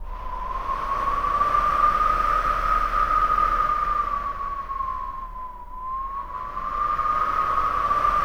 WIND HOWL3.wav